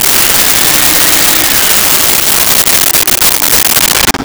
Shop Vac On Off
Shop Vac On Off.wav